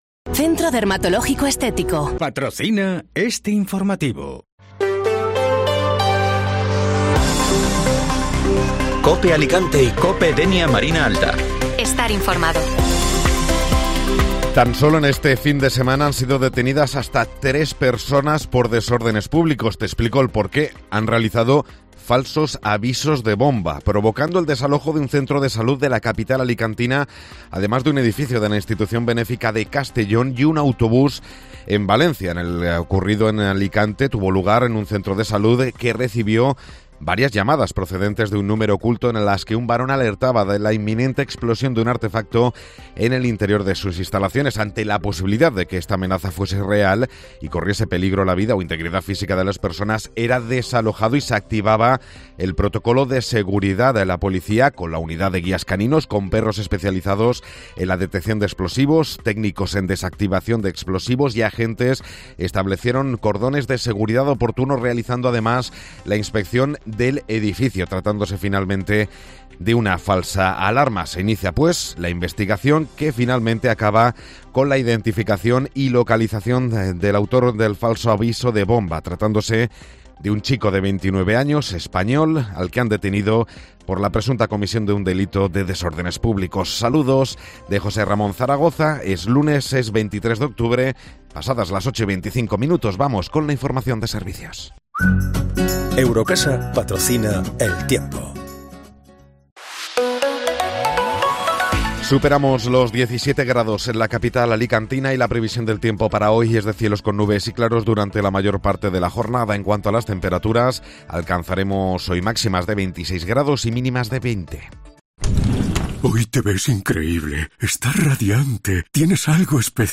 Informativo Matinal (Lunes 23 de Octubre)